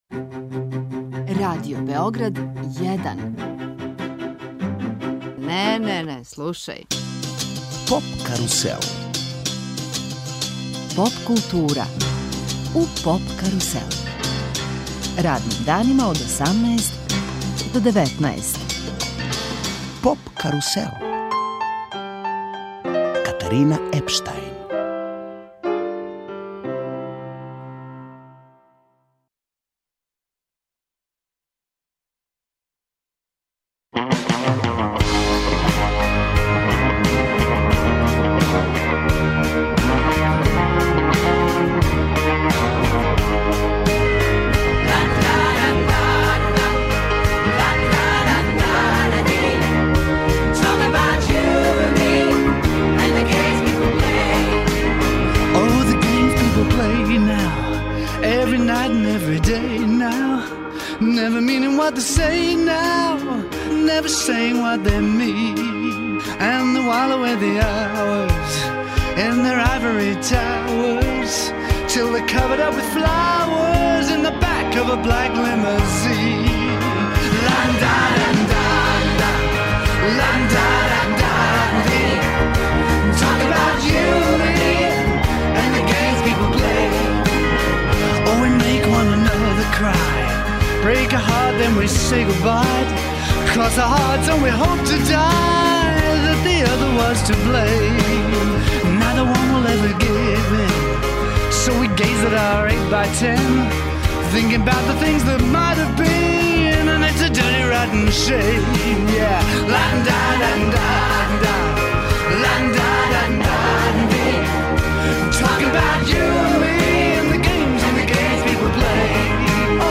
Гост данашње емисије Поп карусел је Дејан Цукић - певач, музичар, новинар и писац.